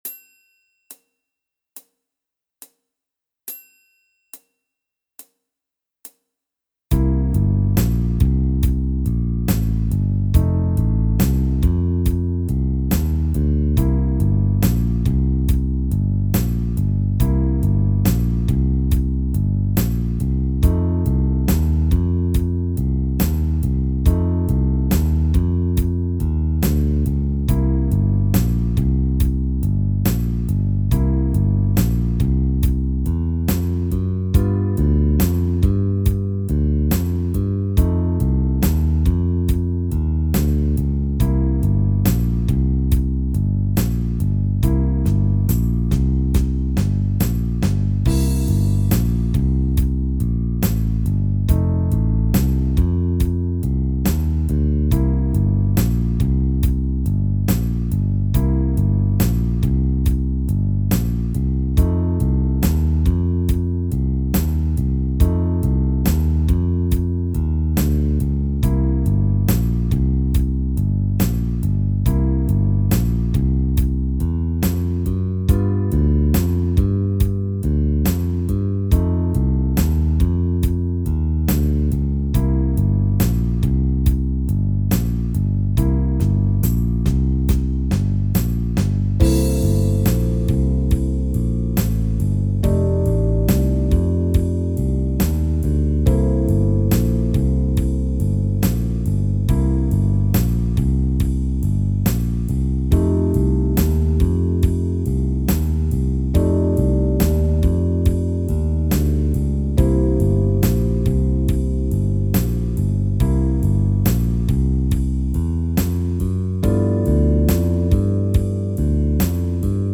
Méthode pour Piano